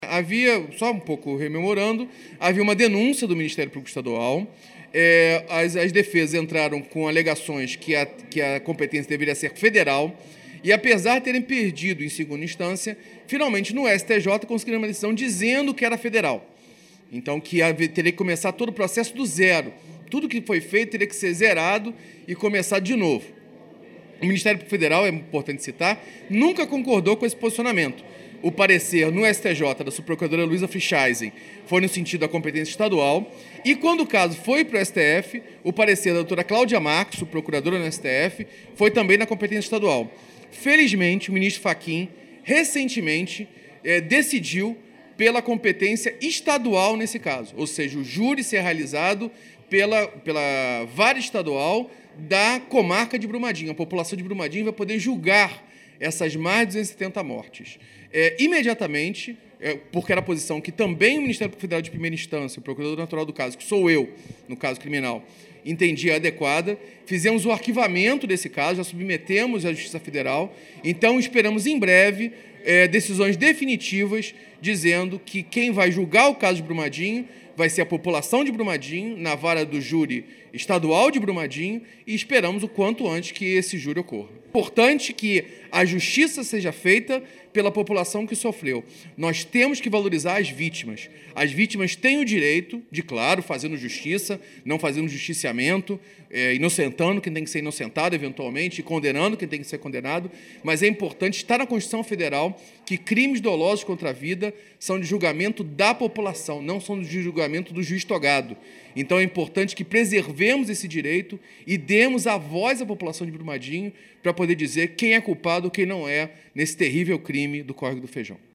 O Portal GRNEWS ouviu o procurador da República Carlos Bruno Ferreira da Silva, que atua no Ministério Público Federal – um dos signatários do acordo feito com a Vale para reparação de Brumadinho – e coordena também a Força-Tarefa Rio Doce-Brumadinho.